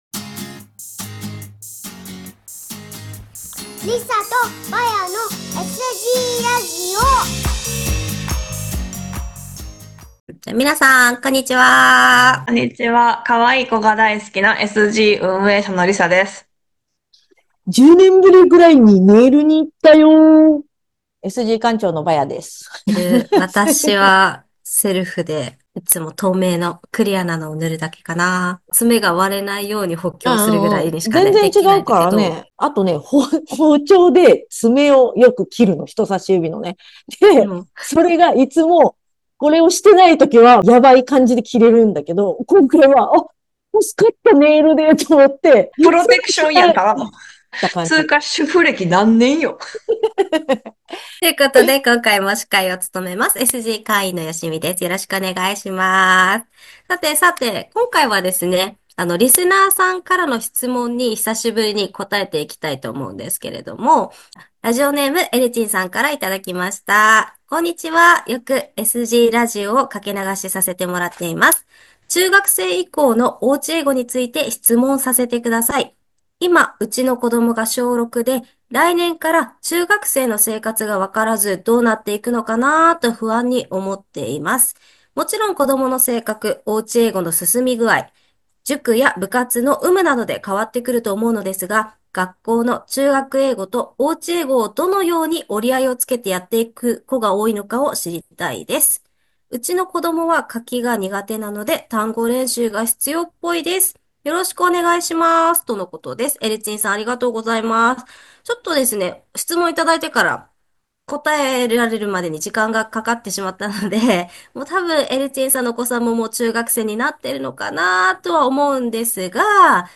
Vol.70「中学生の親の本音！インタビュー1/2」